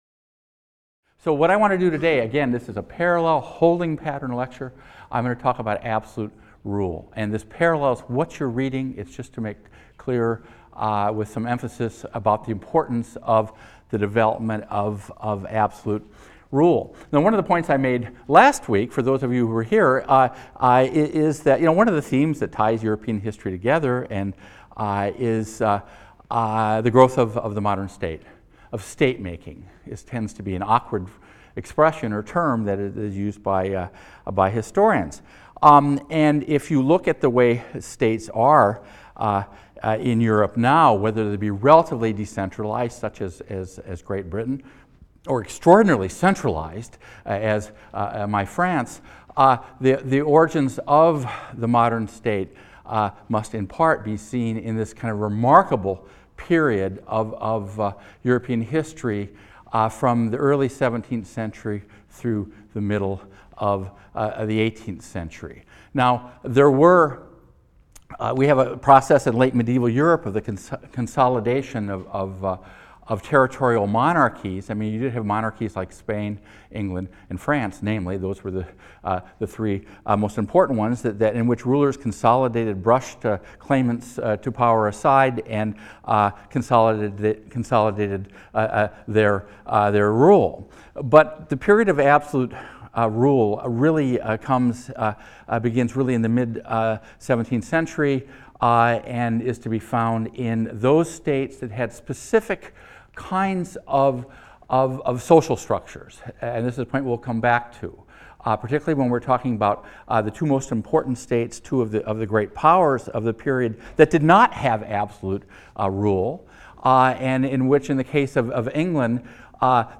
HIST 202 - Lecture 2 - Absolutism and the State | Open Yale Courses